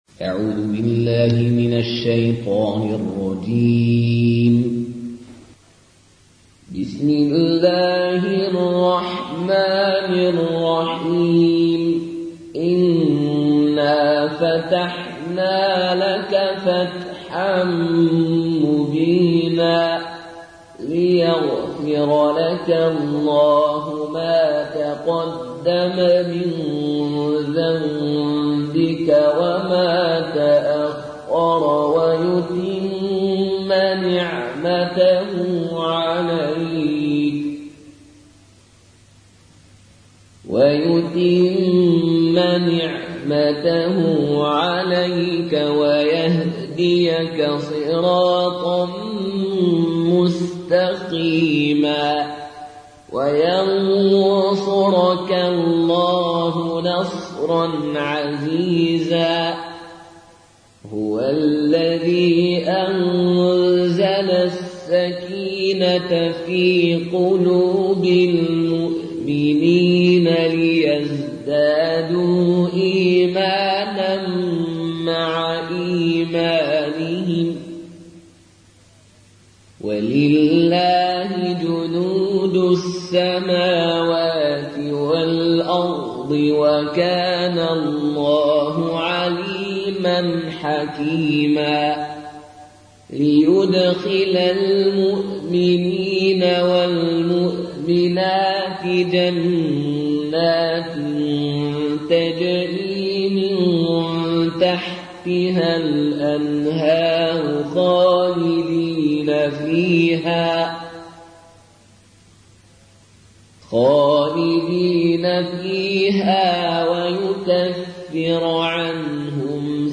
Qaloon an Nafi